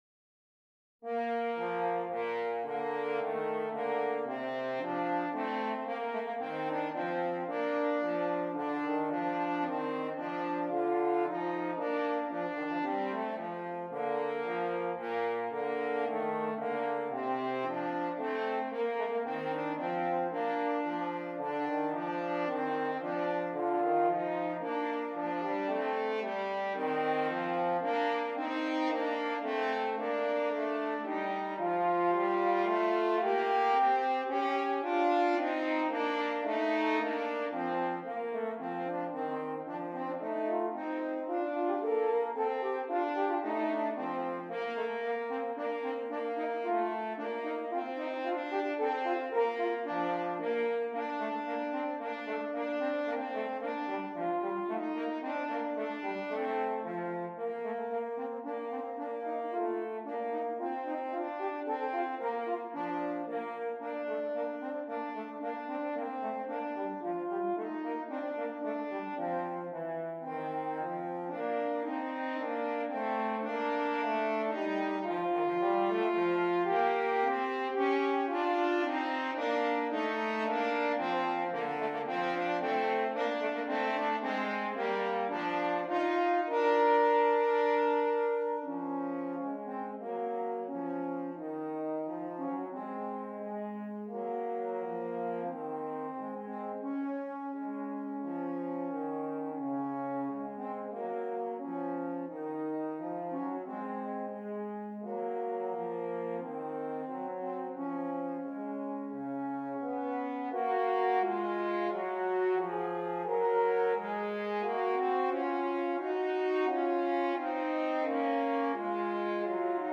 2 F Horns